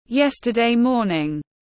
Sáng hôm qua tiếng anh gọi là yesterday morning, phiên âm tiếng anh đọc là /ˈjes.tə.deɪ ˈmɔː.nɪŋ/
Yesterday morning /ˈjes.tə.deɪ ˈmɔː.nɪŋ/